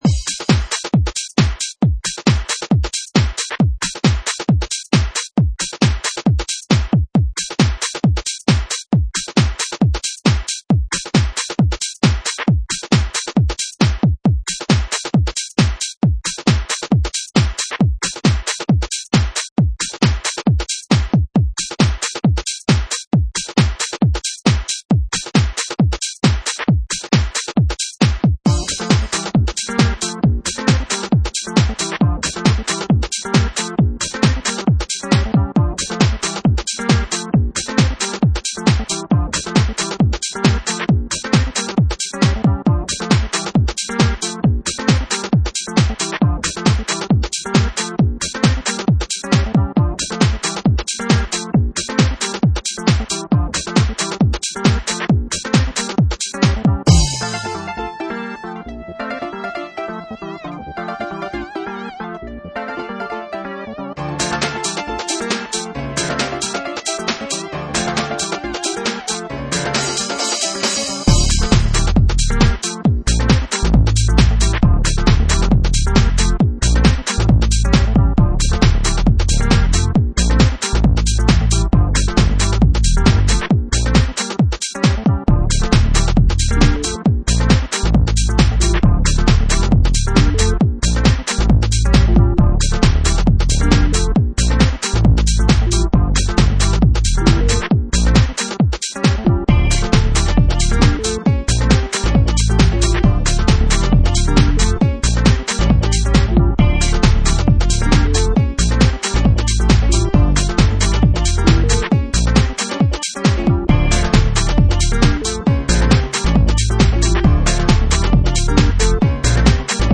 dance/electronic
UK-garage